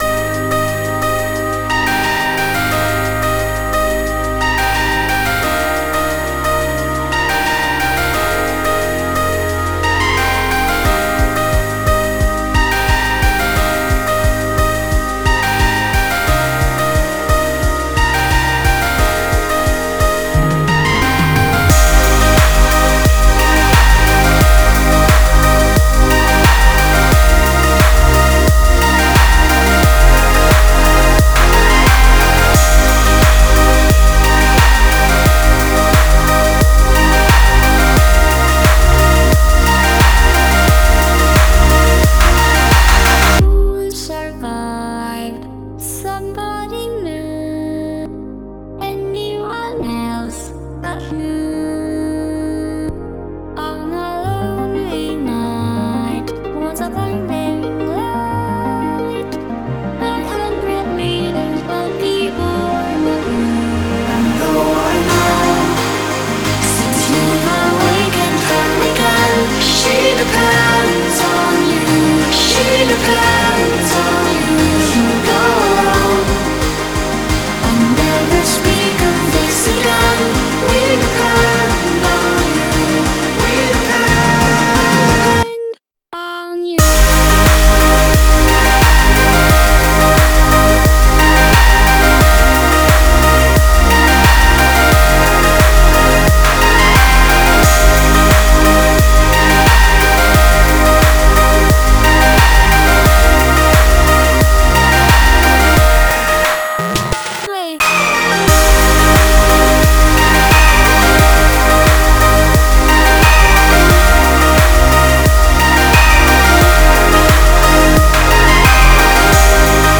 BPM89-177
Audio QualityPerfect (High Quality)